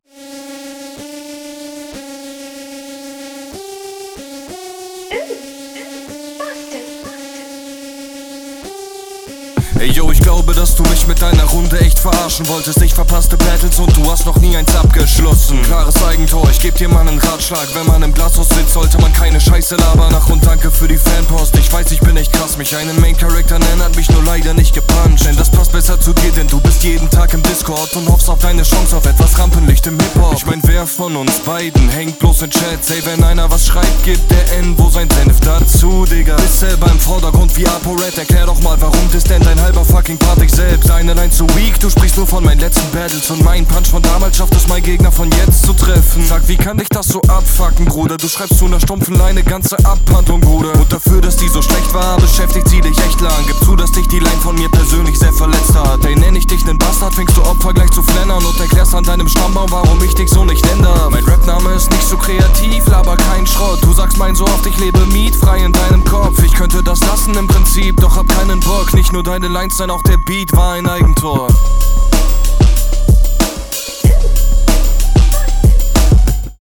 - Geiler Beatpick der zum Stimmbild passt - Keine Representer-Lines oder flache Ich-Fick-Deine-Mutter-Kacke, sonder Gegnerbezug …
Der Beat geht gut nach vorne, die Quali ist ziemlich gut und …
Schön prägnanter, druckvoller Stimmeinsatz, gut auf den Gegner eingegangen, erkennbare Recherche, schön von oben herab.